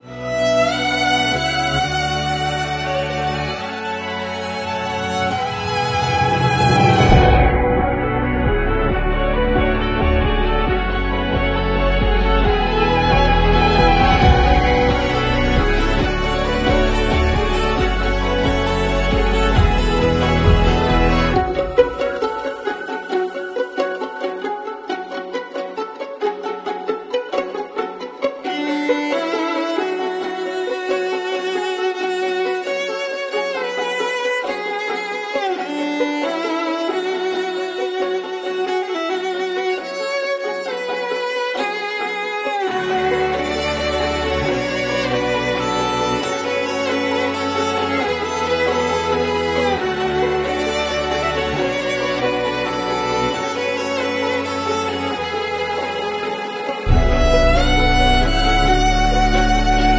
Violin instrumental